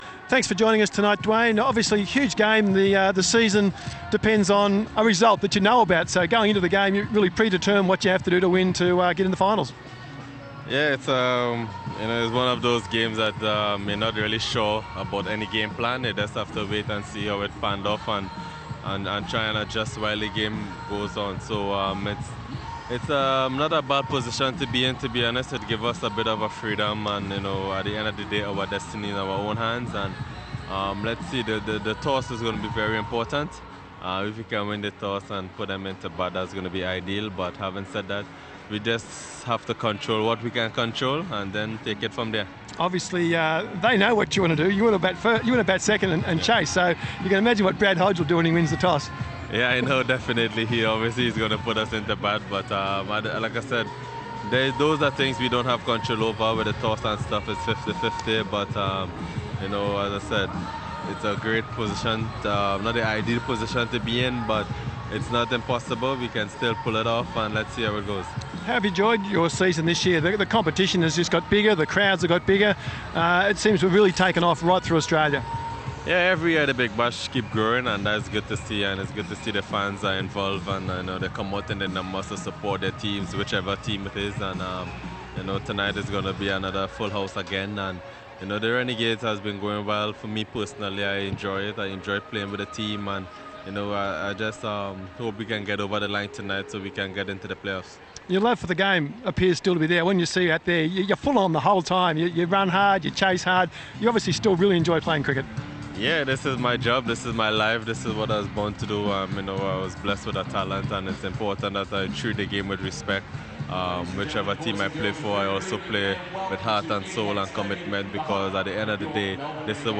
INTERVIEW: Dwayne Bravo talks about the Renegades hopes against Adelaide and his BBL05 campaign